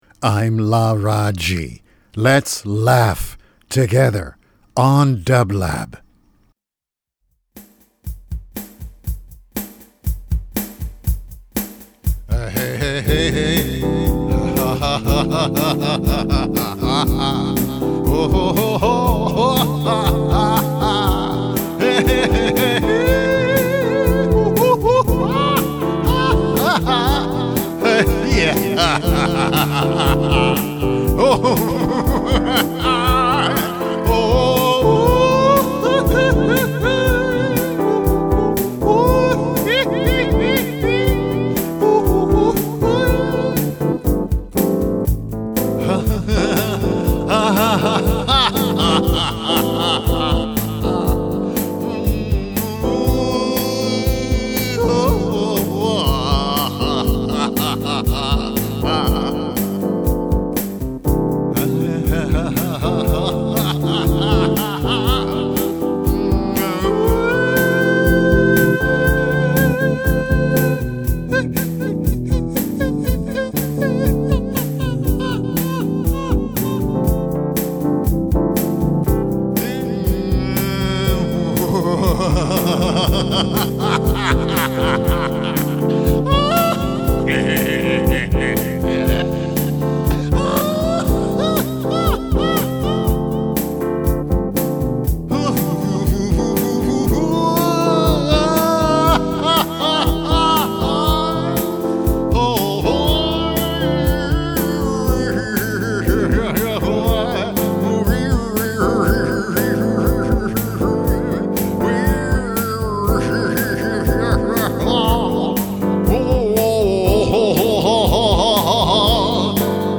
LARAAJI LAUGHS ~ a laughter meditation (09.28.23) - dublab
Every Thursday, Laraaji energizes the dublab airwaves with three minutes of luminous laughter flowing on celestial music beds on LARAAJI LAUGHS.